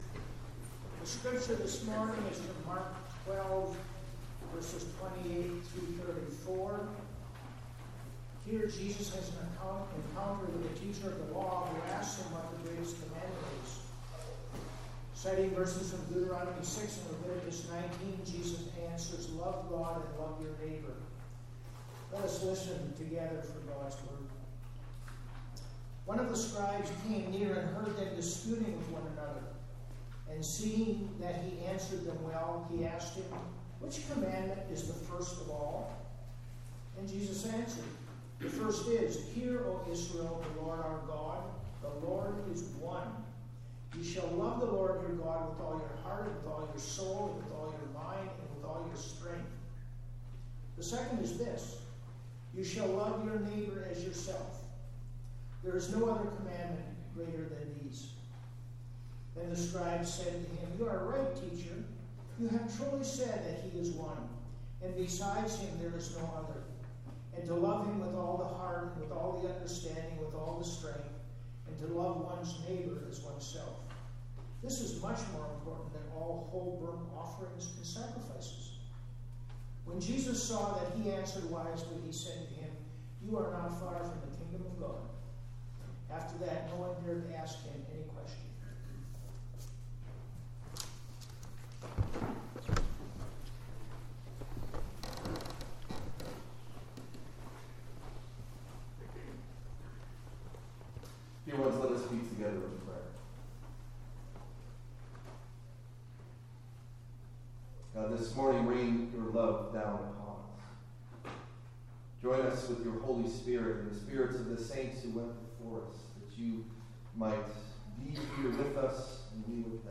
Meditation Delivered at: The United Church of Underhill (UCC and UMC)